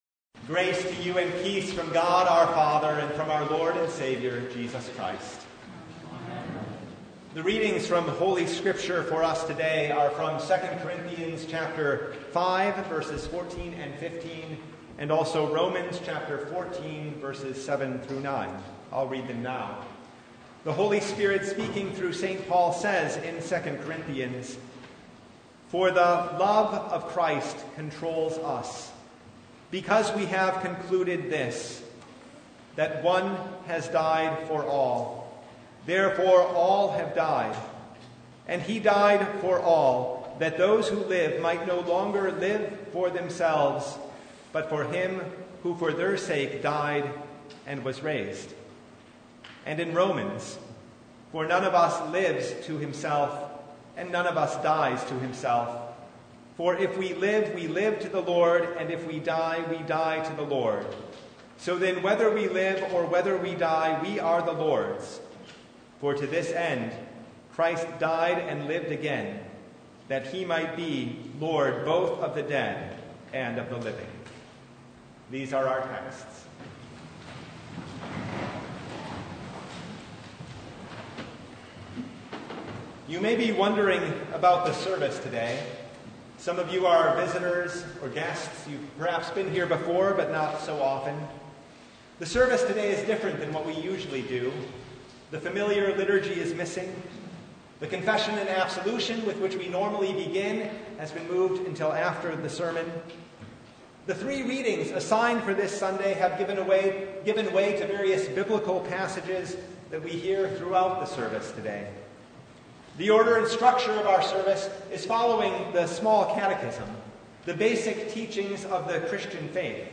Service Type: Consecration Sunday
Sermon Only